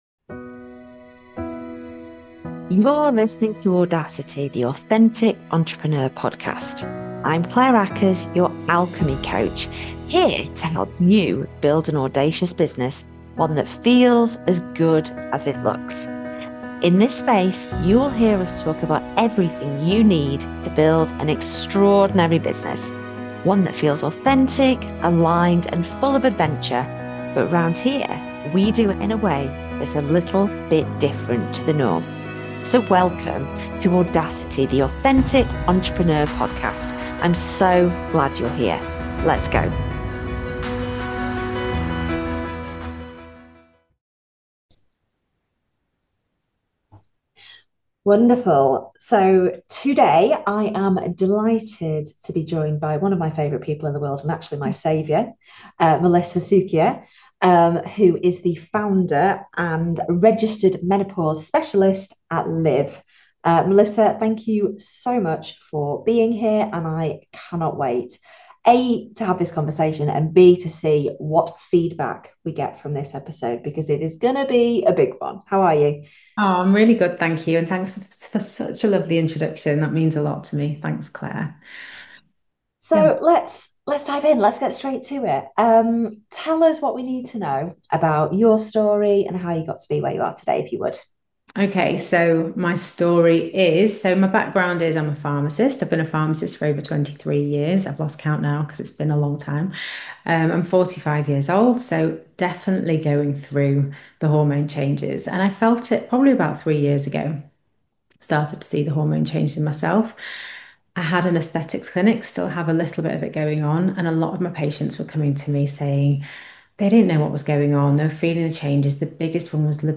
In this extremely candid conversation